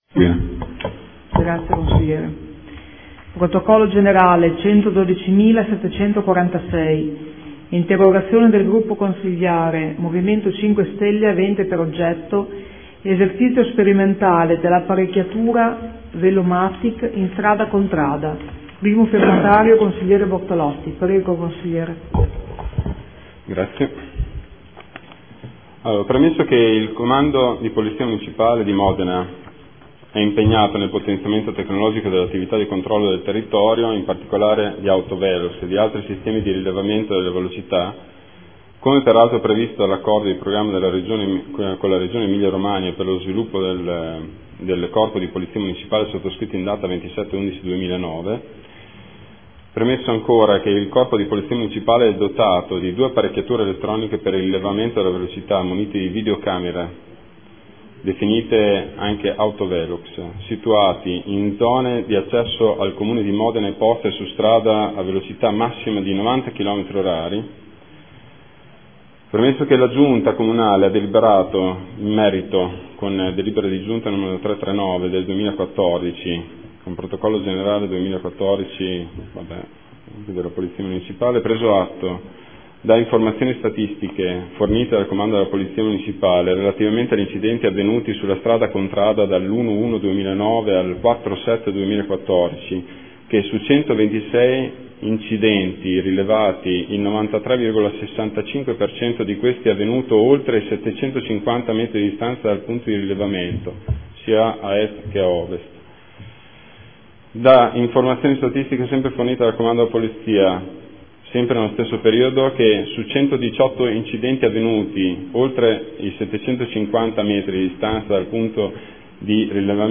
Marco Bortolotti — Sito Audio Consiglio Comunale